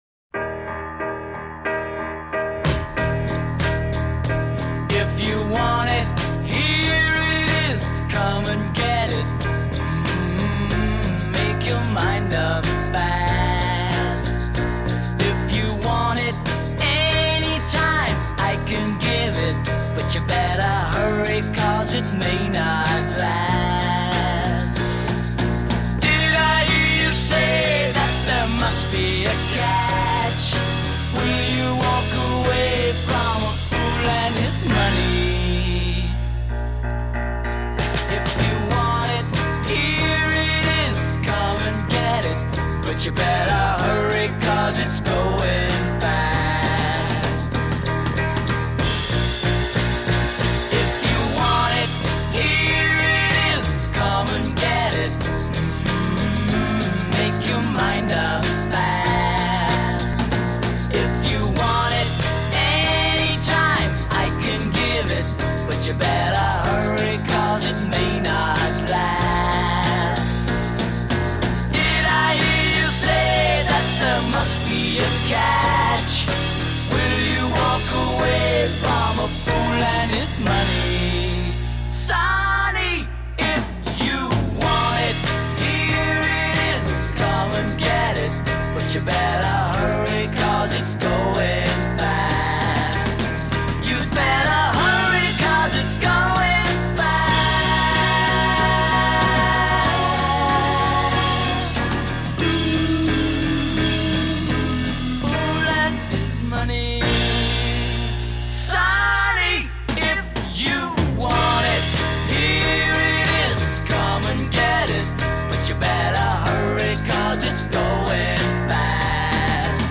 Recorded 2 August 1969 at the EMI Recording Studios, London.
Intro 4 piano, add bass and drums
verse 8 vocals (add tambourine) a
chorus 10 add vocal harmonies c
chorus 8 + 2 harmonies enhanced c